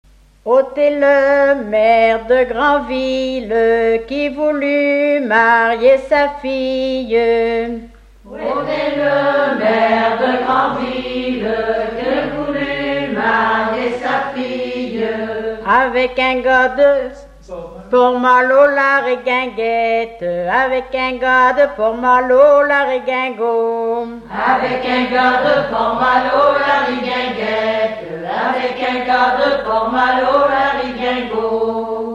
Genre laisse
repertoire de chansons traditionnelles
Catégorie Pièce musicale inédite